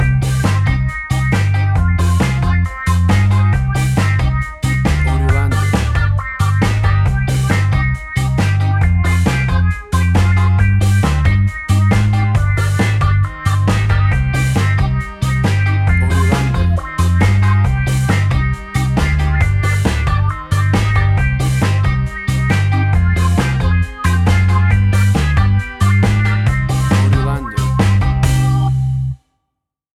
Classic reggae music with that skank bounce reggae feeling.
WAV Sample Rate: 16-Bit stereo, 44.1 kHz
Tempo (BPM): 136